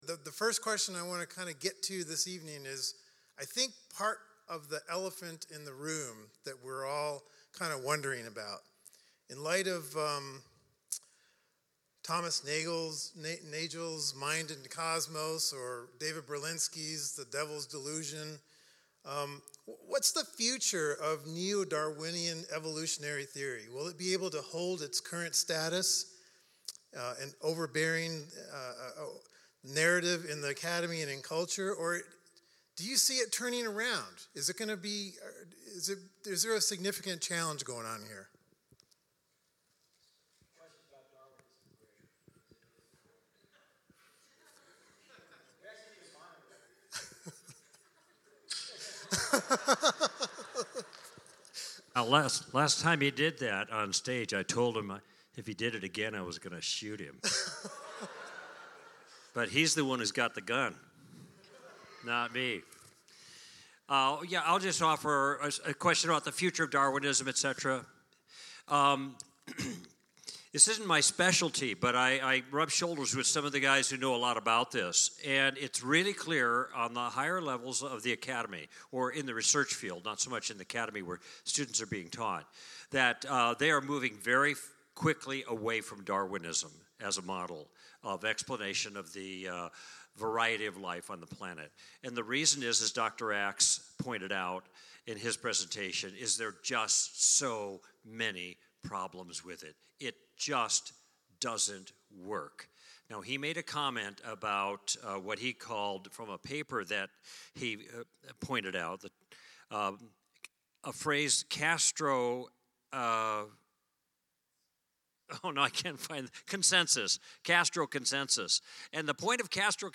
2020 · undefined Insight Is 2020: Apologetics Conference Panel Discussion and Q&A